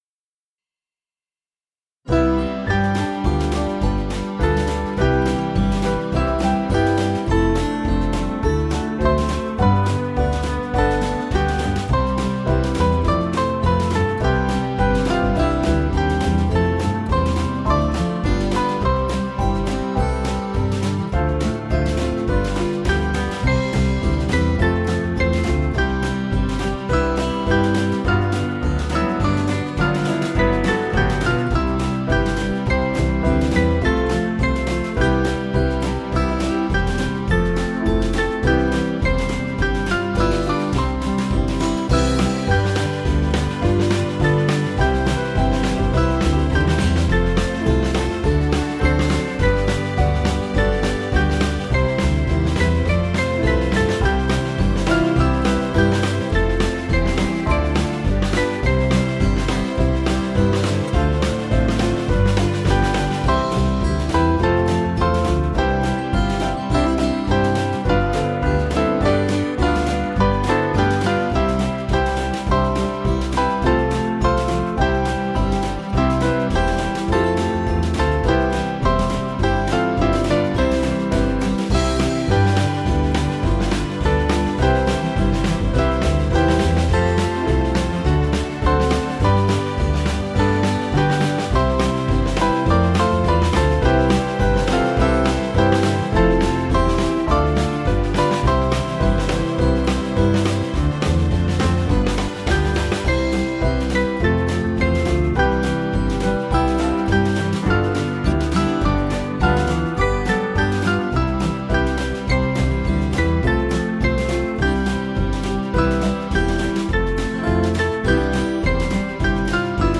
Small Band
(CM)   4/C-Db